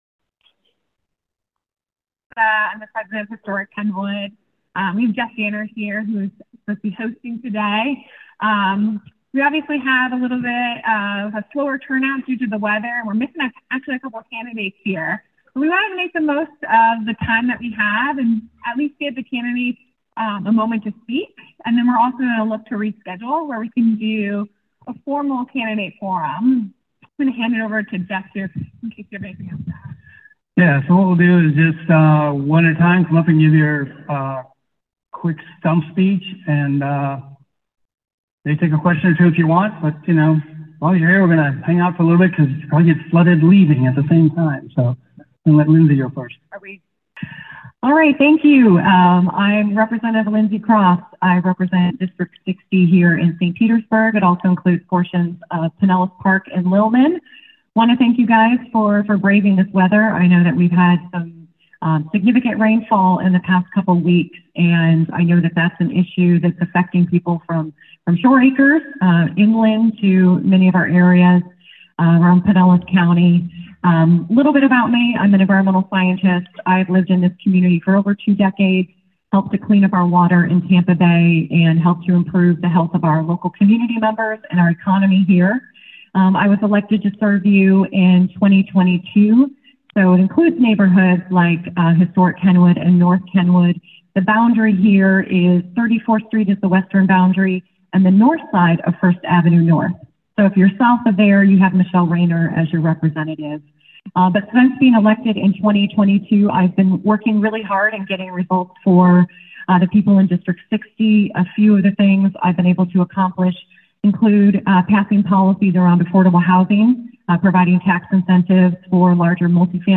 "Candidates in Kenwood" 9-4-24 St. Petersburg High School
Candidates for various positions in St. Petersburg introduced themselves and their platforms, with a focus on issues such as affordable housing, public health, and infrastructure improvements.